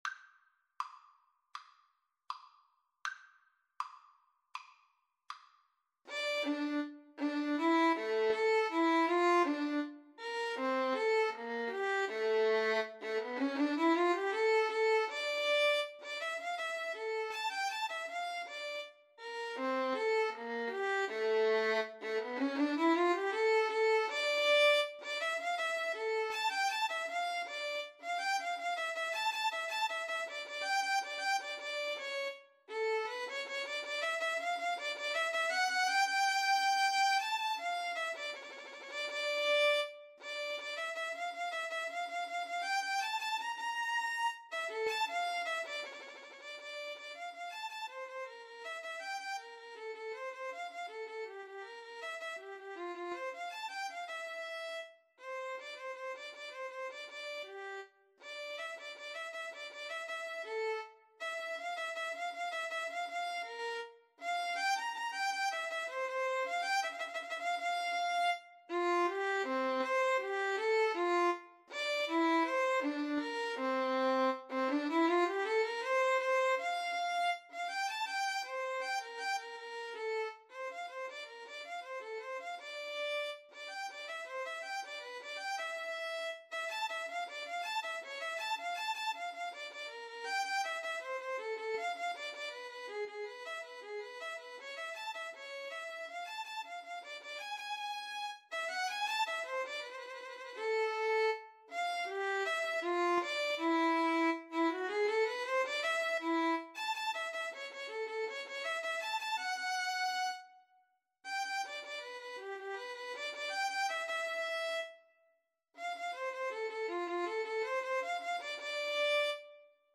Classical (View more Classical Violin-Viola Duet Music)